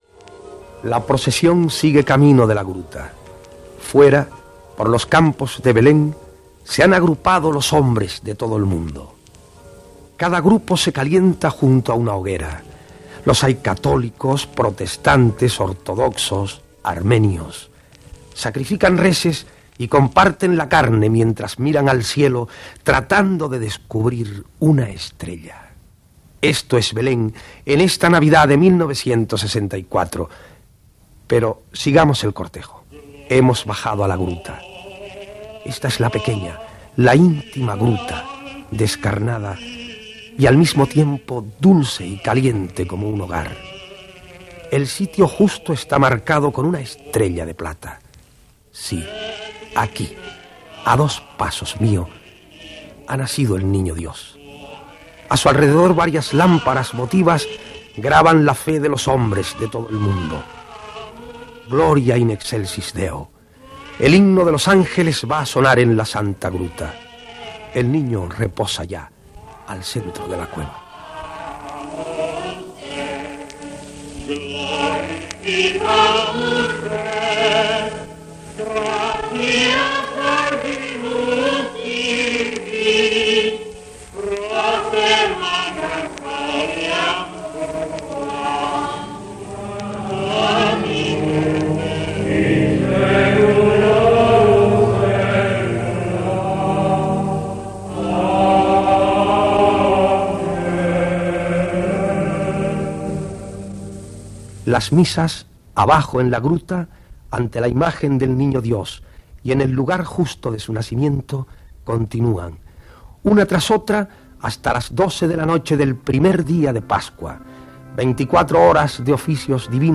Fragment extret del programa "Audios para recordar" de Radio 5 emès el 22 de desembre del 2014.